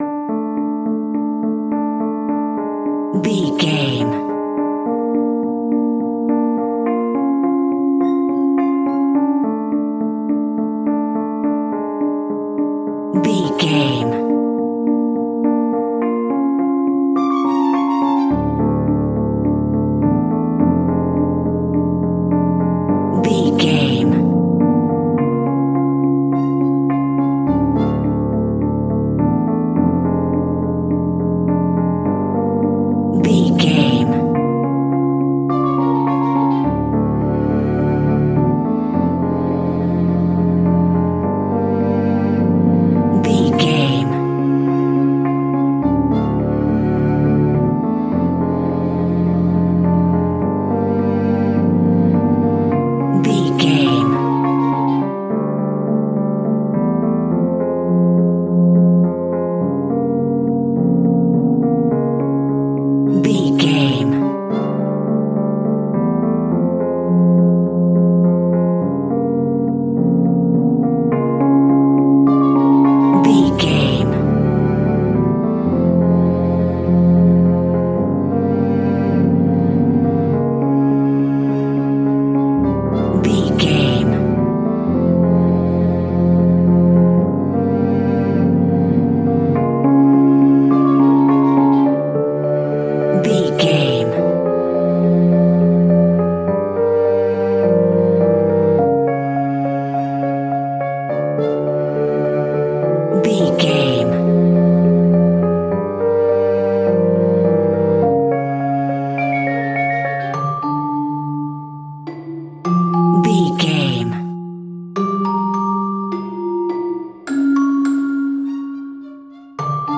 Aeolian/Minor
scary
tension
ominous
dark
suspense
haunting
eerie
strings
percussion
synth
pads